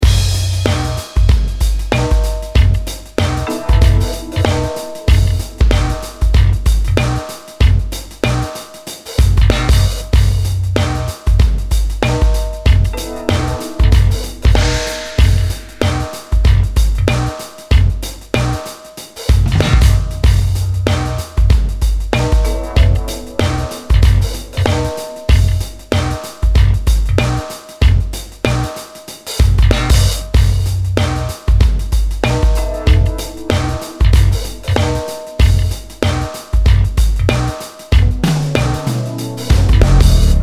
Купил легко подержанный сабж и сразу трабла - воткнув наушники (AKG K240) и выкрутив контроллеры СTL ROOM/SUBMIX и MAIN MIX до отметки "U" (т.е. до середины) - услышал явный шум, который усиливался с перемещением контроллеров в право.